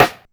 Rimshot6.aif